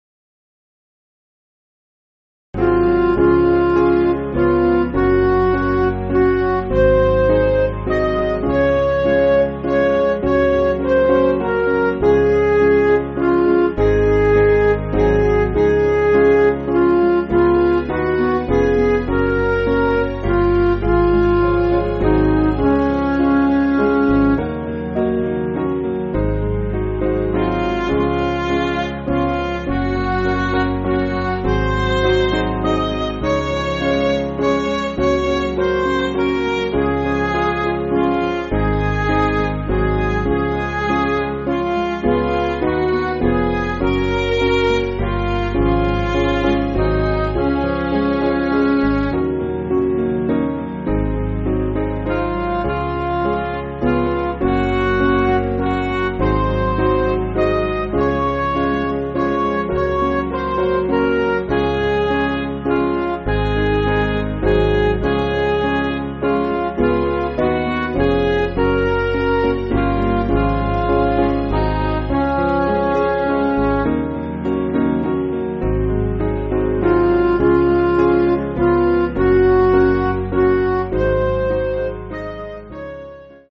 Piano & Instrumental
(CM)   6/Db
Midi